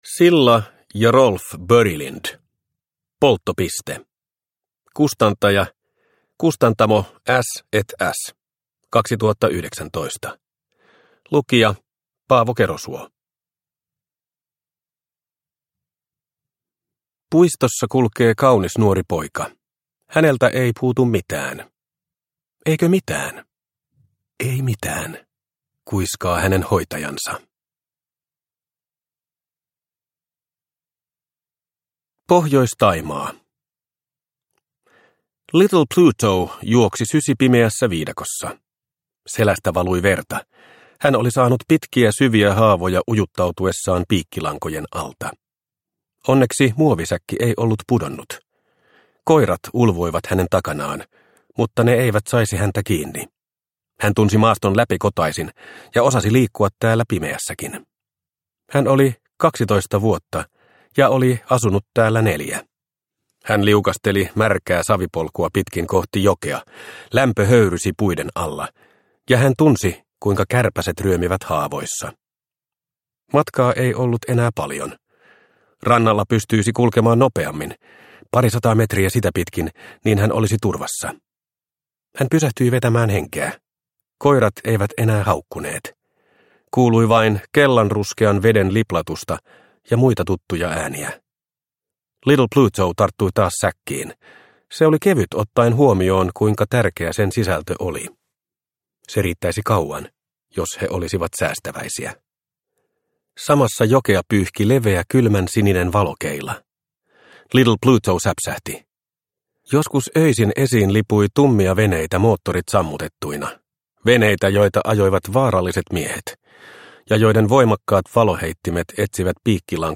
Polttopiste – Ljudbok – Laddas ner